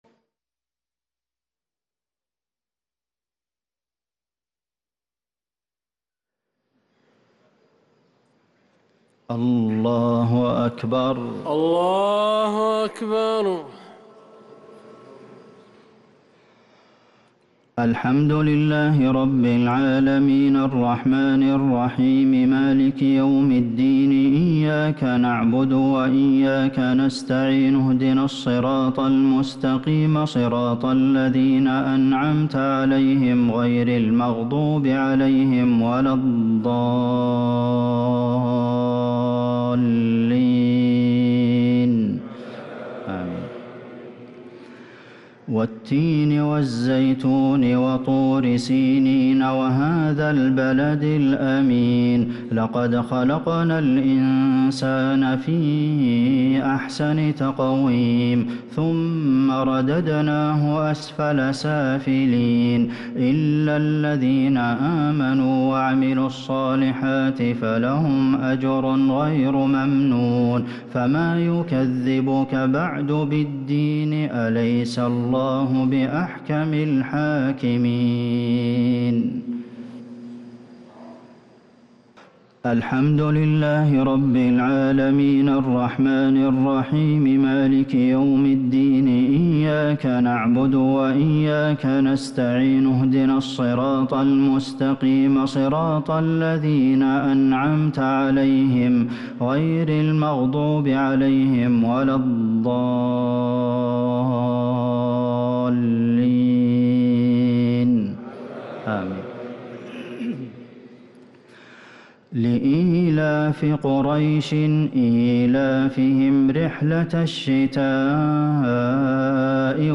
الشفع و الوتر ليلة 26 رمضان 1444هـ | Witr 26 st night Ramadan 1444H > تراويح الحرم النبوي عام 1444 🕌 > التراويح - تلاوات الحرمين